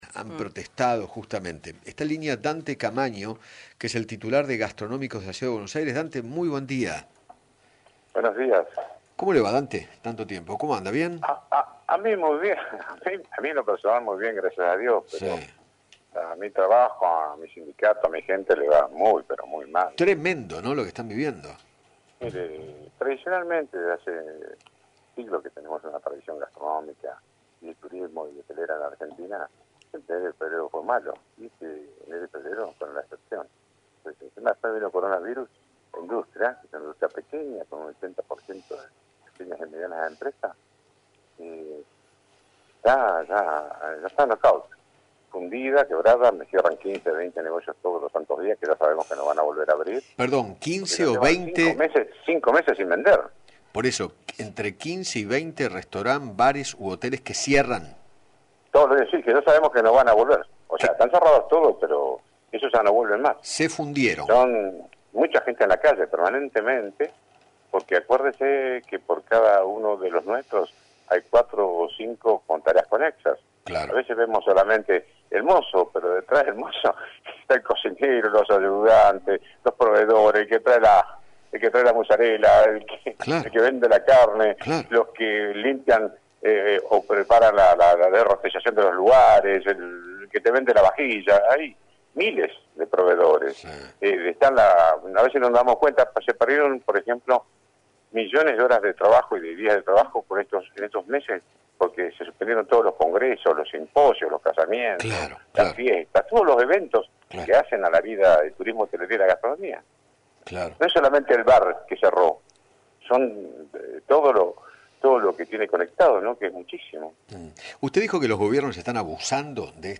dialogó con Eduardo Feinmann sobre el fuerte impacto de la cuarentena en el sector gastronómico y se refirió al reclamo para que reabran restaurantes